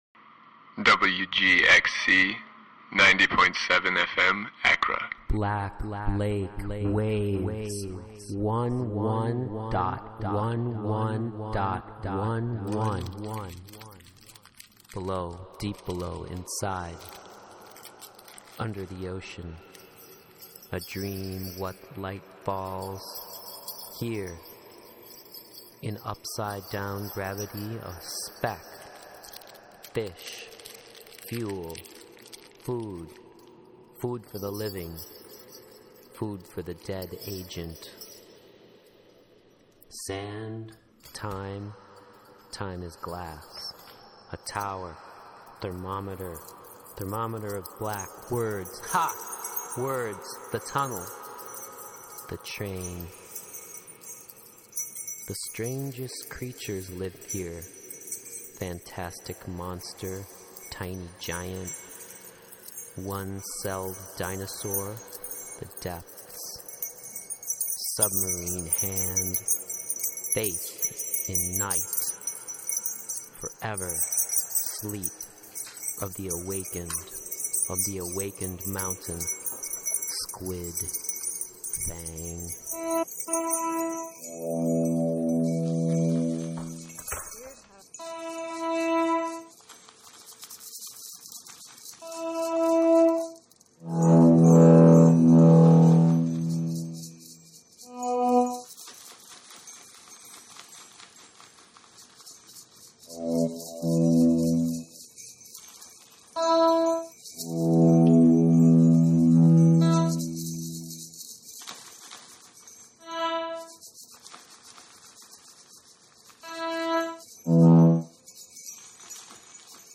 Local band Black Lake takes over the guest DJ slot...